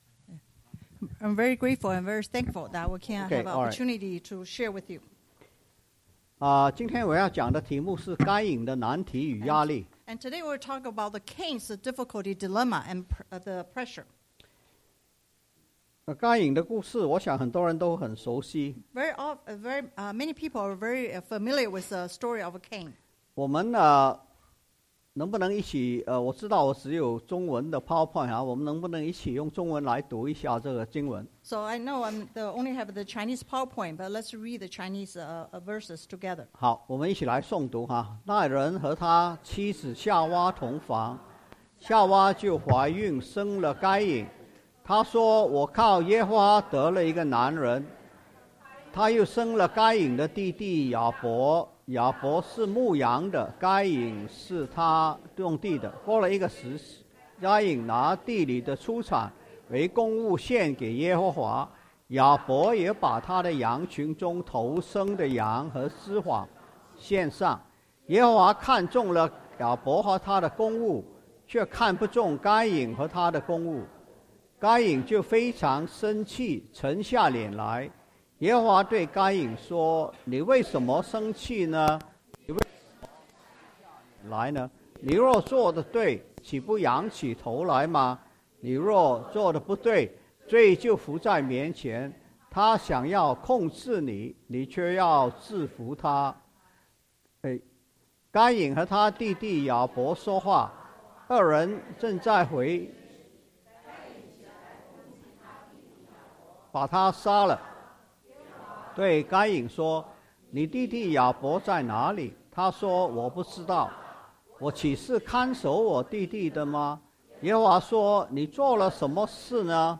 Genesis 4:1-12 Service Type: Sunday AM Bible Text